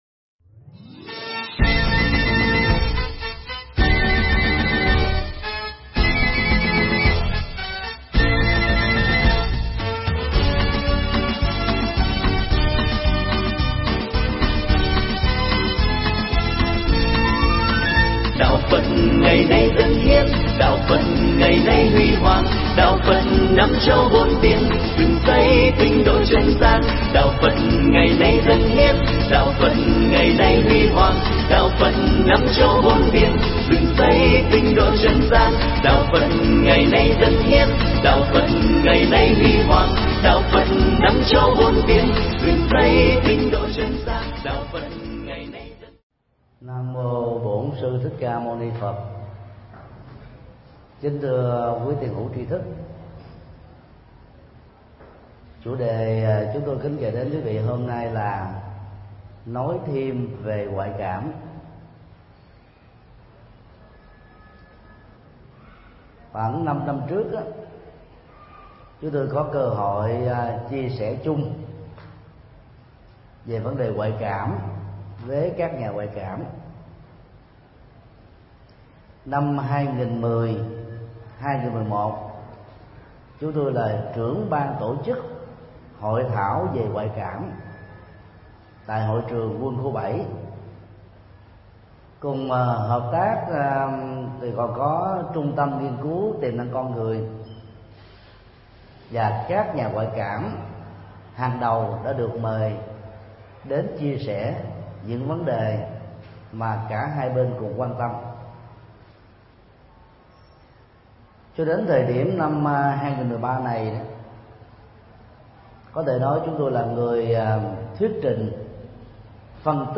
Pháp âm Nói thêm về ngoại cảm – thầy Thích Nhật Từ mp3
Tải mp3 Pháp thoại Nói thêm về ngoại cảm do thầy Thích Nhật Từ Giảng tại chùa Xá Lợi, ngày 18 tháng 11 năm 2013